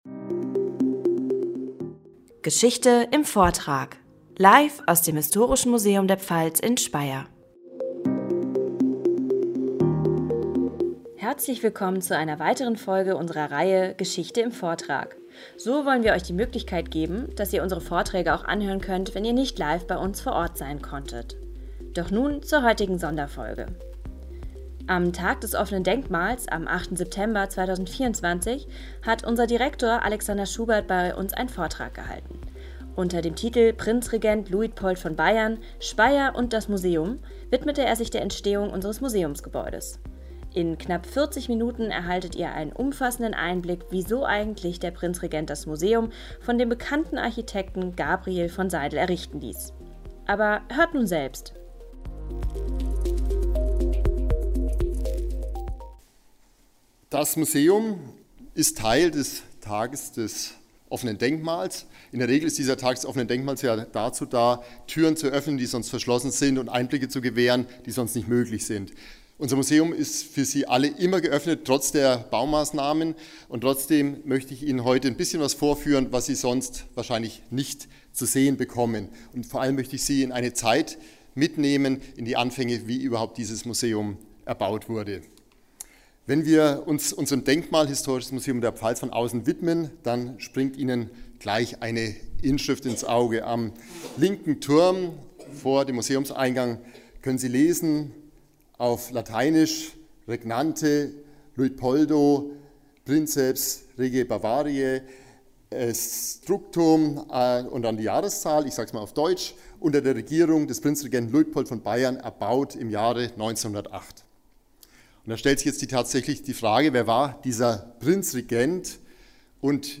Die Reihe "Geschichte im Vortrag", die ebenfalls auf Spotify und auch bei Podigee zu finden ist, bietet die Aufzeichnung einzelner Vorträge aus dem Begleitprogramm zur Ausstellung für alle, die nicht live vor Ort sein konnten.
Vortrag_TdoD.mp3